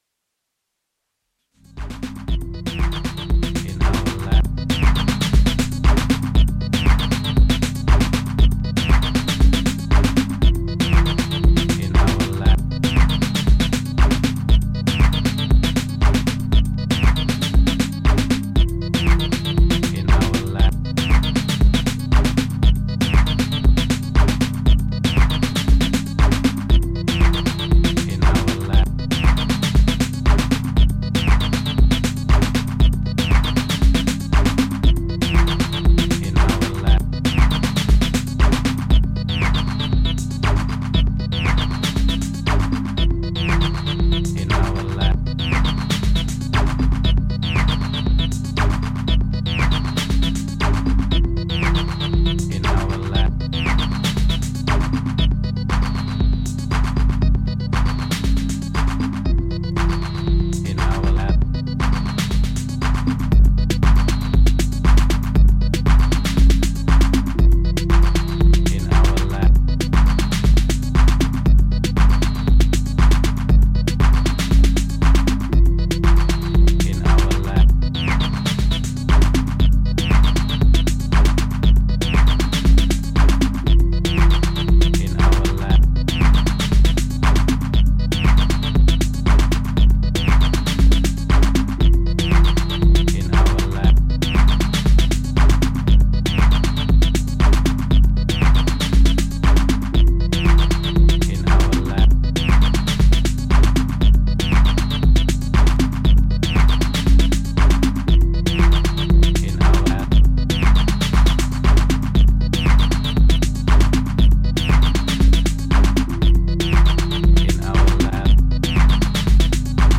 ジャンル(スタイル) TECHNO / TECH HOUSE / DEEP HOUSE